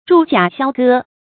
铸甲销戈 zhù jiǎ xiāo gē
铸甲销戈发音